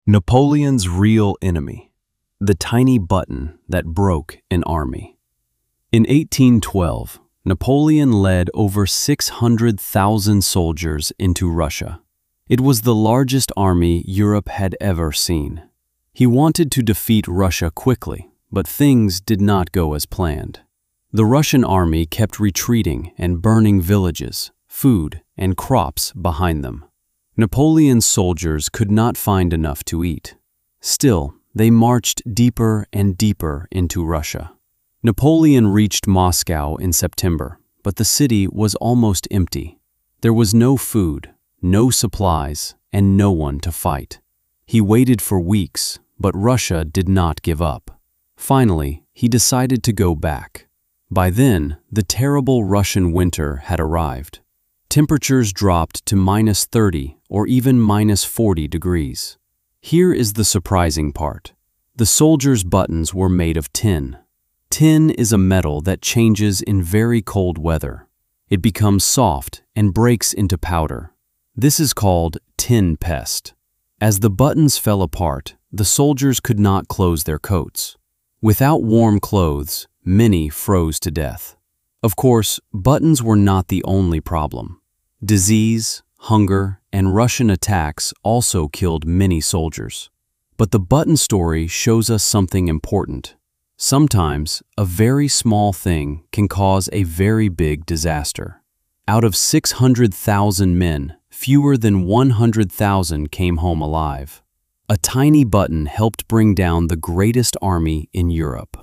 🔊 音読用音声